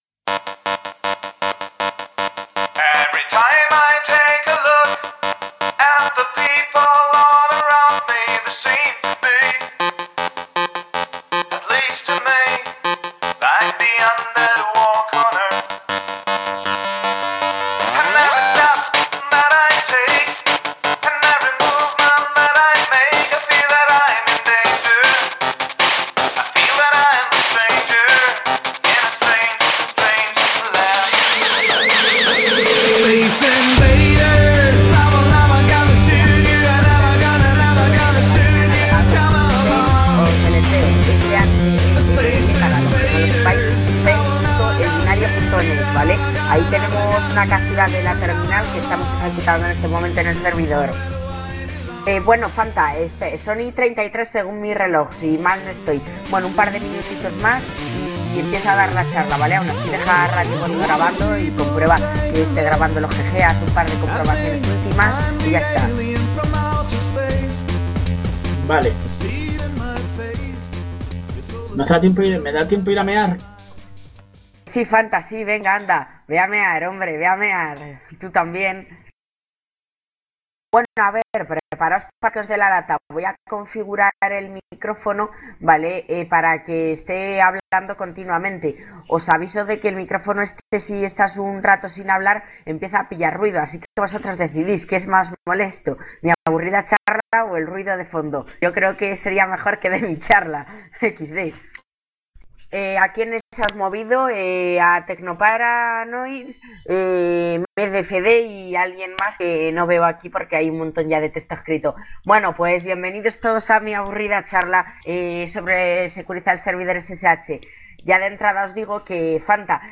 Adquirir servidor y dominio anónimamente. Securizar un servidor SSH - Charla